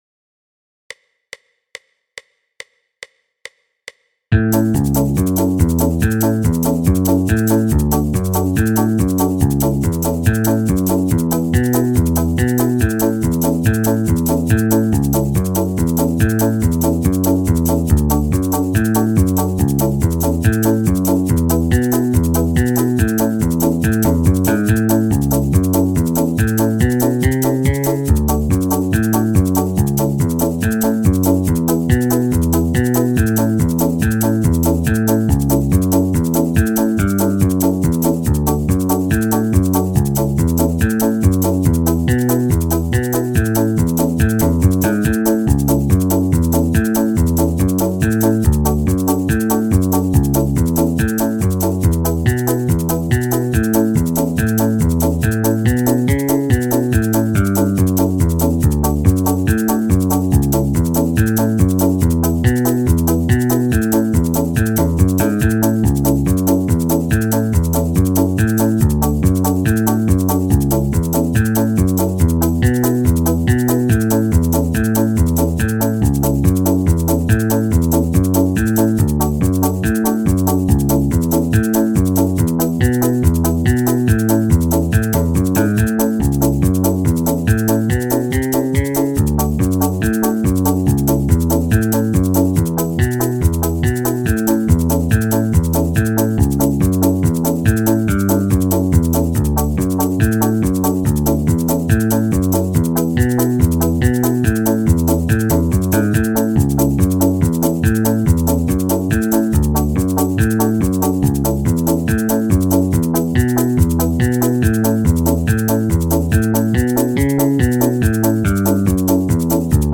This is a very popular Bluegrass song